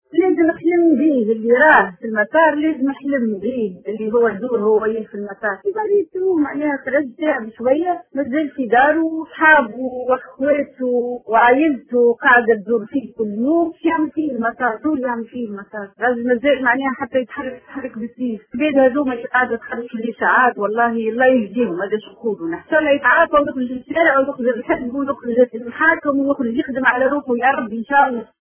S’exprimant au micro de Tunisie Numérique